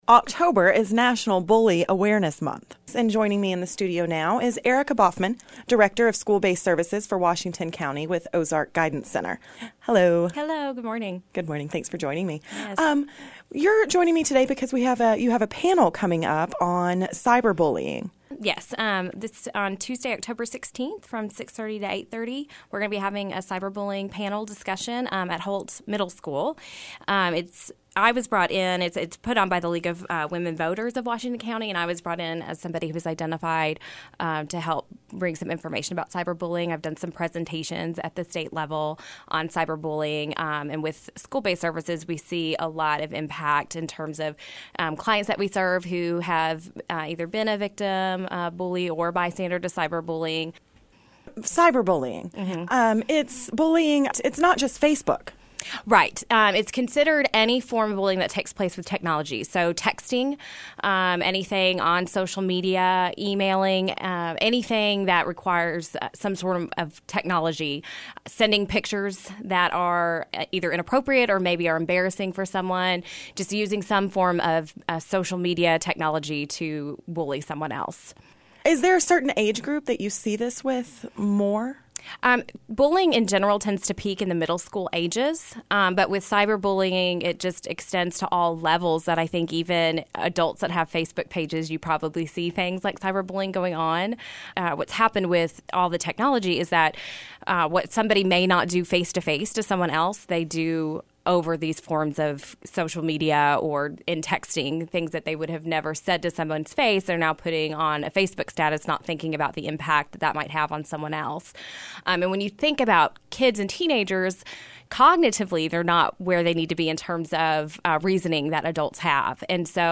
talked with one of the panelists